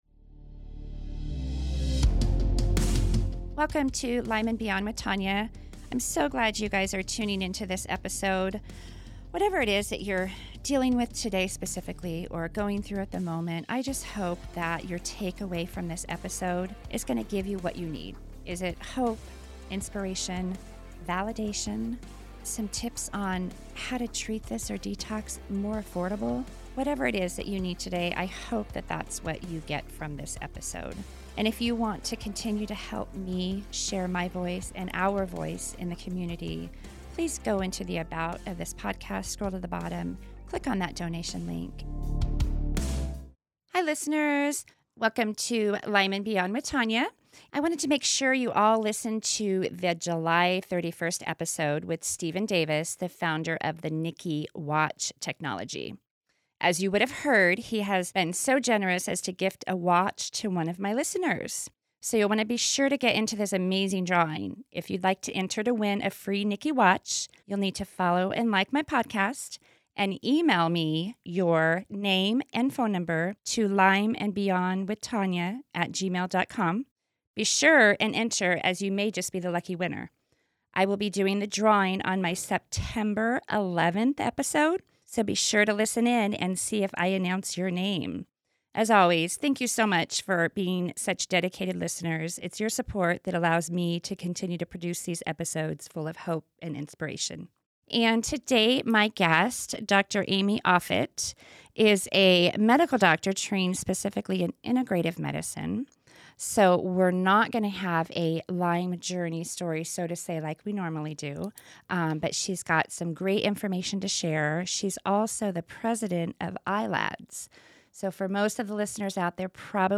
A New Approach to Lyme Disease: A Conversation